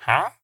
Minecraft Version Minecraft Version 1.21.4 Latest Release | Latest Snapshot 1.21.4 / assets / minecraft / sounds / mob / villager / haggle1.ogg Compare With Compare With Latest Release | Latest Snapshot
haggle1.ogg